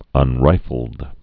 (ŭn-rīfəld)